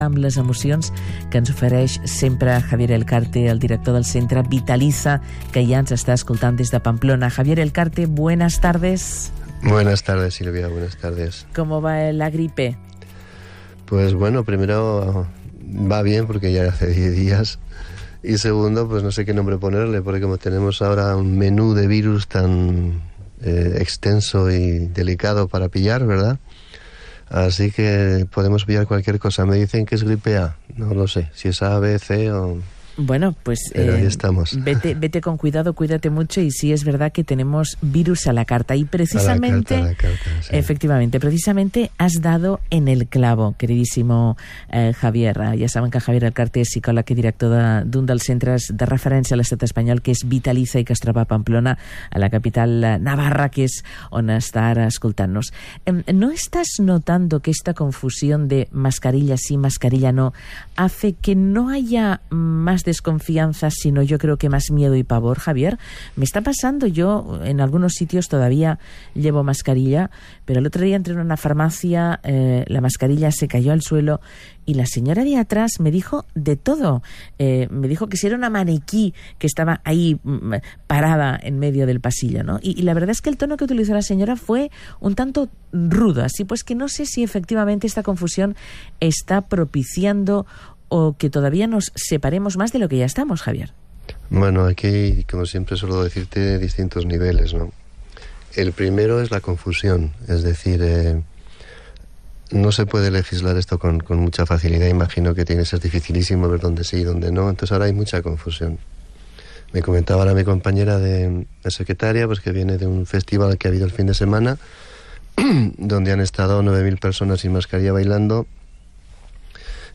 Ambos charlan en esta ocasión, sobre lo que supone para muchas personas el quitarse la mascarilla o no.